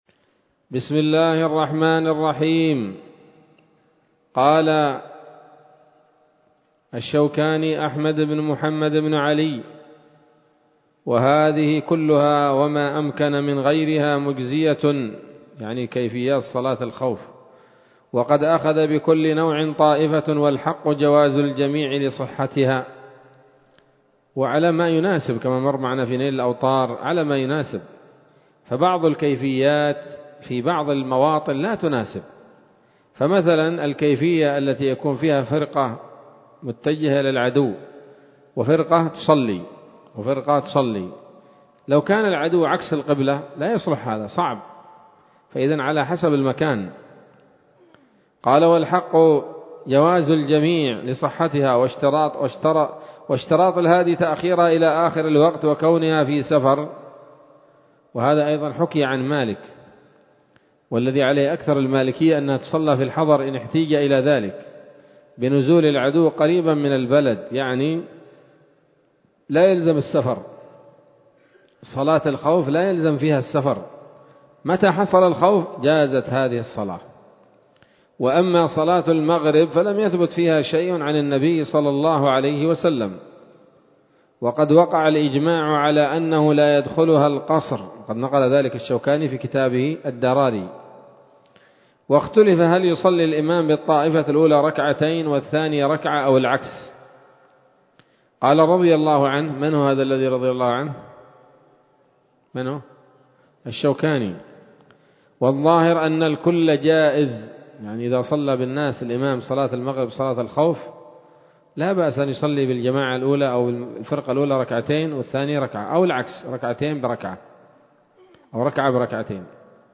الدرس الخمسون من كتاب الصلاة من السموط الذهبية الحاوية للدرر البهية